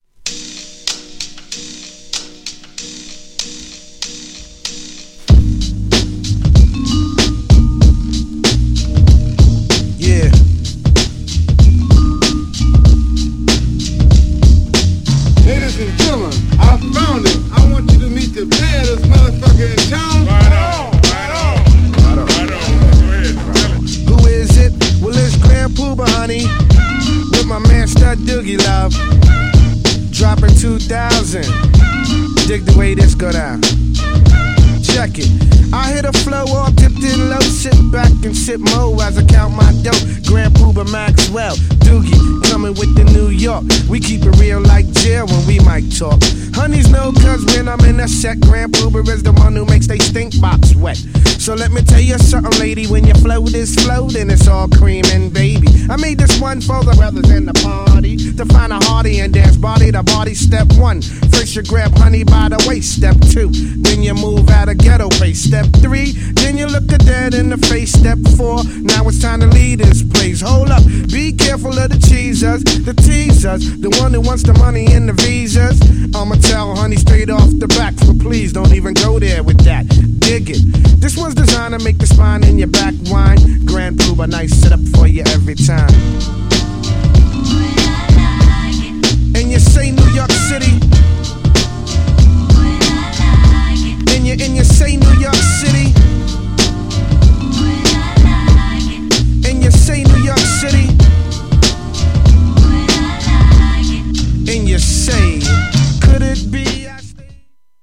GENRE Hip Hop
BPM 86〜90BPM